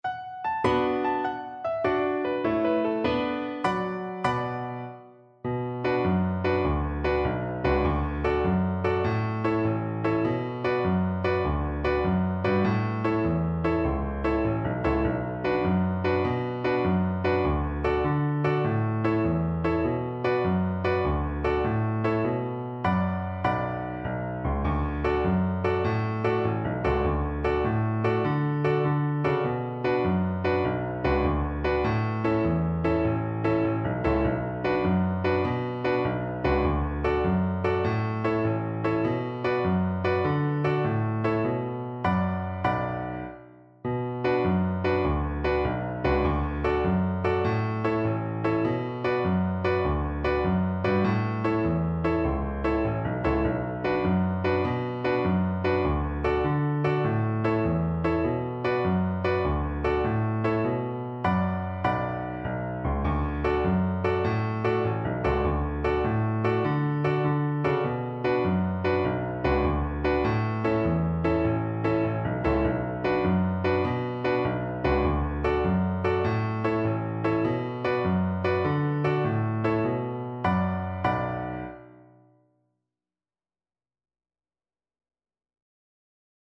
Viola
B minor (Sounding Pitch) (View more B minor Music for Viola )
Allegro .=c.100 (View more music marked Allegro)
6/8 (View more 6/8 Music)
Traditional (View more Traditional Viola Music)